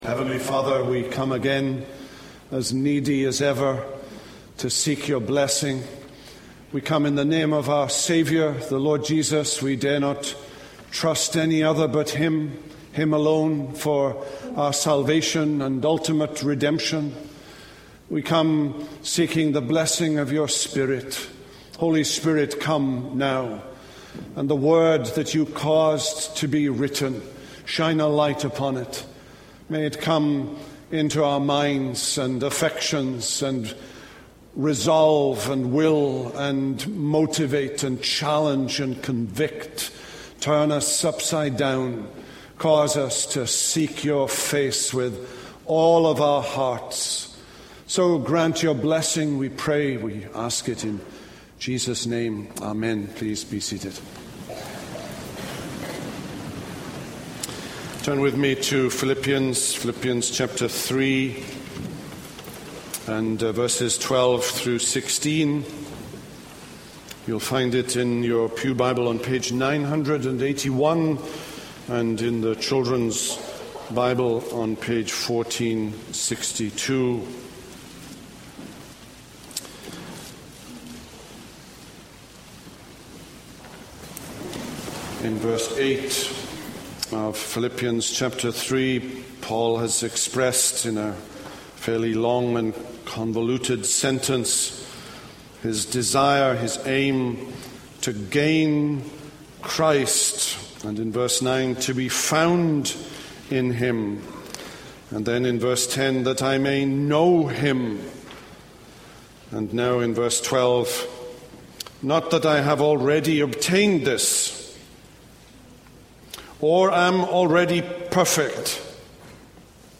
This is a sermon on Philippians 3:12-16.